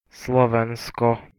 2. ^ /slˈvækiə, -ˈvɑːk-/ [9][10] Slovak: Slovensko [ˈslɔʋenskɔ]
Sk-Slovensko.ogg.mp3